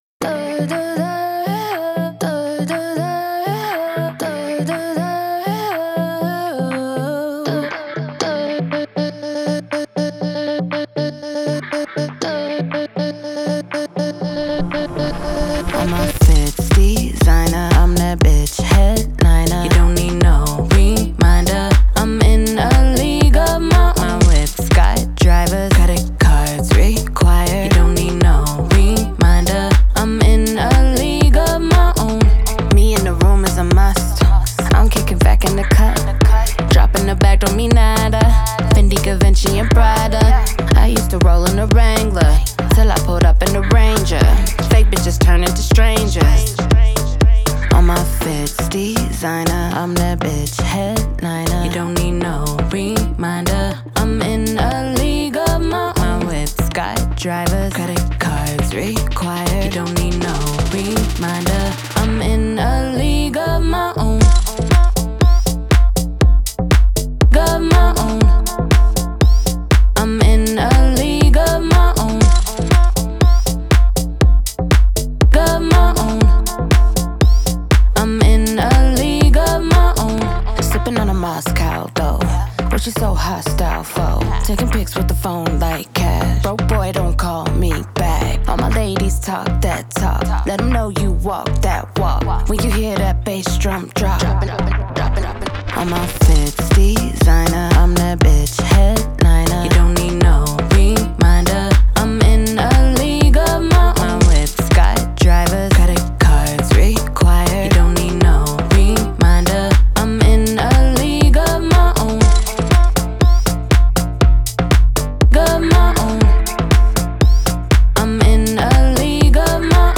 Hip Hop, Pop